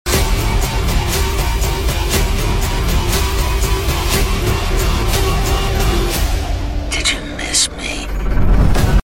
did-u-miss-me-scream-7.mp3